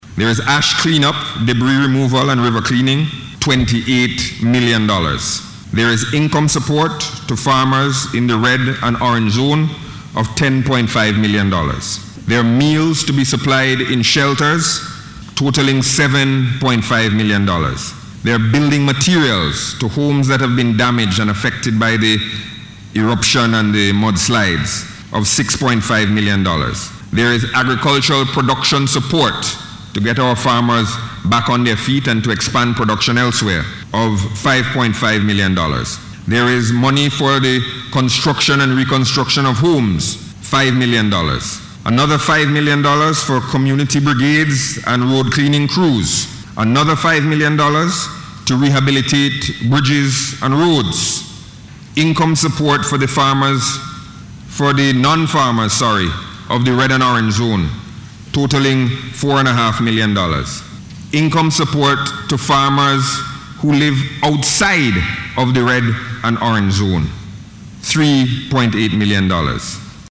This was disclosed by Finance Minister Camillo Gonsalves, as he presented the Supplementary Appropriation Bill 2021 in Parliament this week.